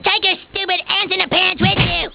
Cartman Screams "More"